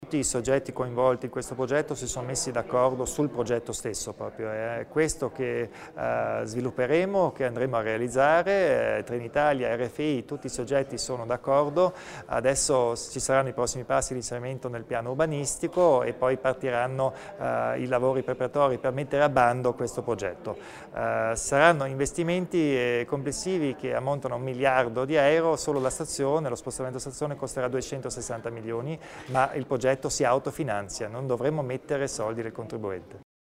Il Presidente Kompatscher sul nuovo areale ferroviario